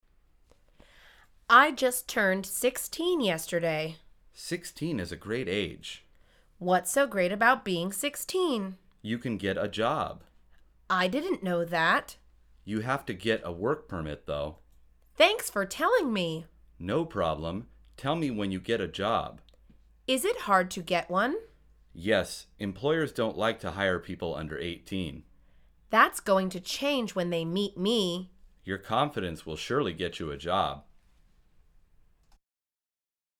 کاردو‌آنلاین |مجموعه مکالمات ساده و آسان انگلیسی: کار در شانزده سالگی